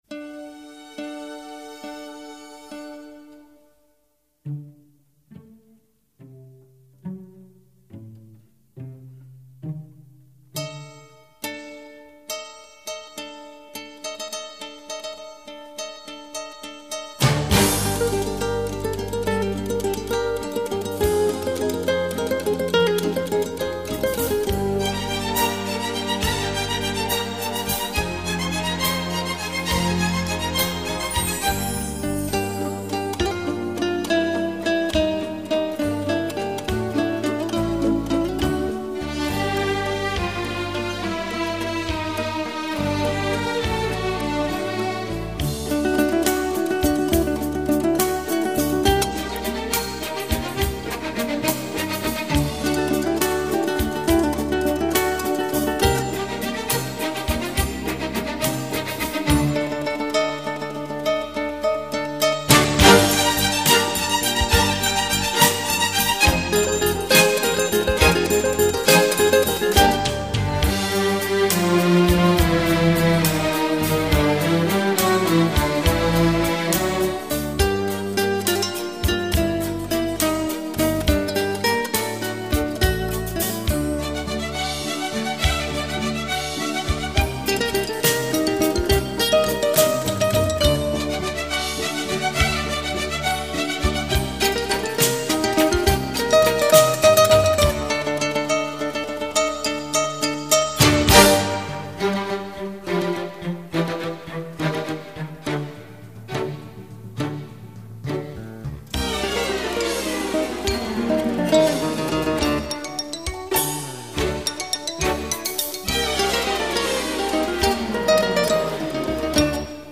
不仅没有幽怨、恐怖的感觉，还焕发出一种热闹、激扬的舞会气氛，使人百听不厌！
需要留意的是此碟电平极低，因此最强音与最弱音之间的动态对比非常明显，给重播器材带来严峻考验。